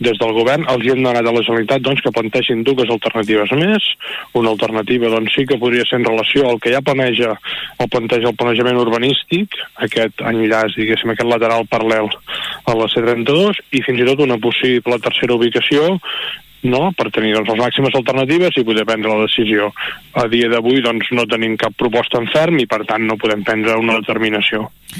Ho ha dit l’alcalde Marc Buch en una entrevista a l’FM i +, en què ha tornat a apel·lar al consens a l’hora de prendre una decisió de tanta trascendència per l’impacte paisatgístic i mediambiental.